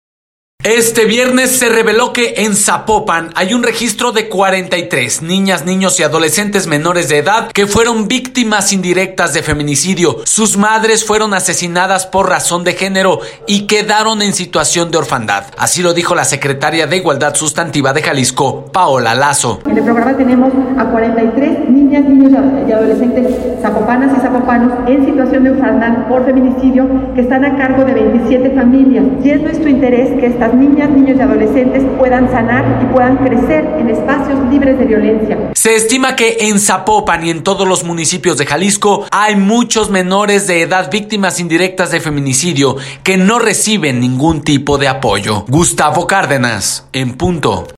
Así lo dijo la secretaria de igualdad sustantiva de Jalisco, Paola Lazo: